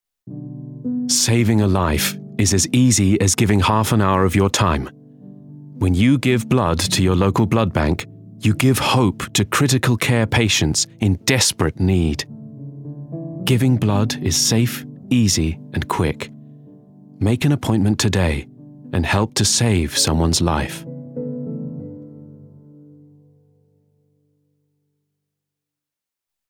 Authentic  |  Warm  |  Versatile
Charity